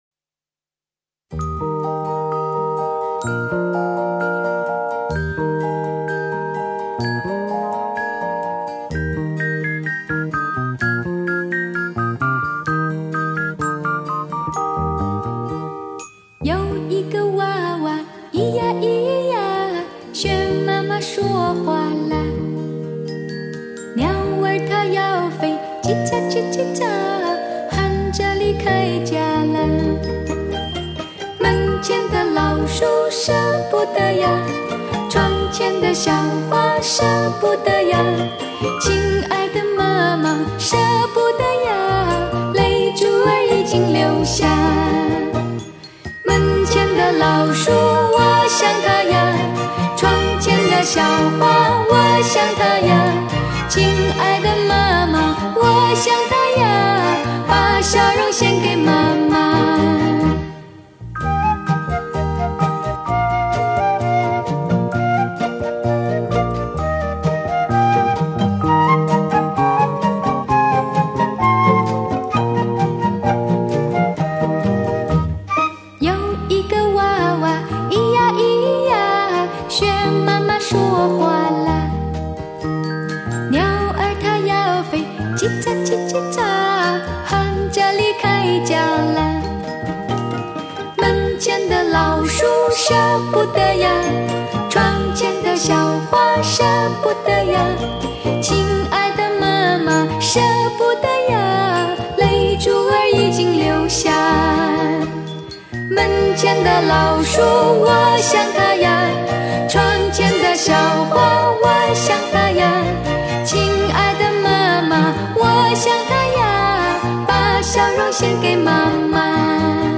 民谣是一把油纸伞，它遮了满世界的风雨，营造出特别的安宁和温馨。
这是一群天使在向你歌唱，每一支旋律都能翻开一段难忘的往事。